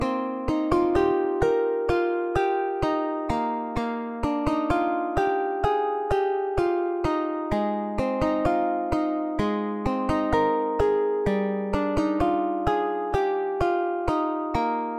弦乐吉他Ovation 2 128
Tag: 128 bpm Dubstep Loops Strings Loops 2.52 MB wav Key : Unknown